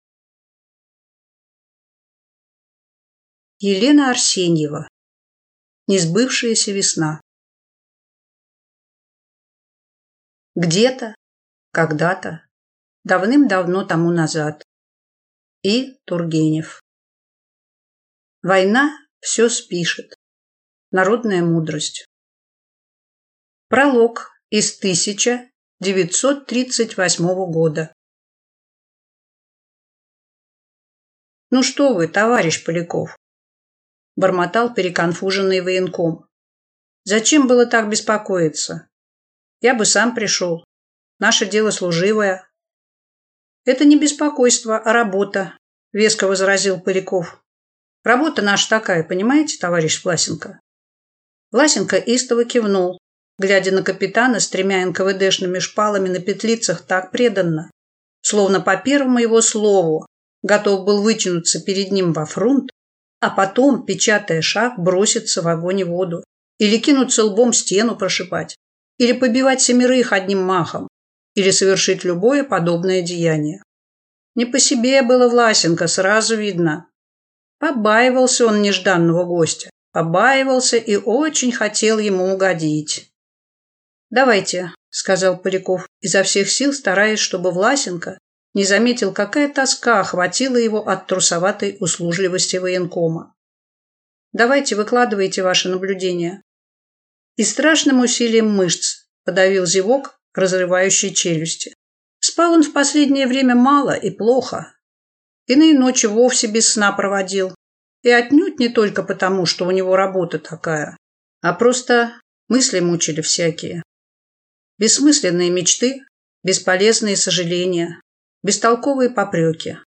Аудиокнига Несбывшаяся весна | Библиотека аудиокниг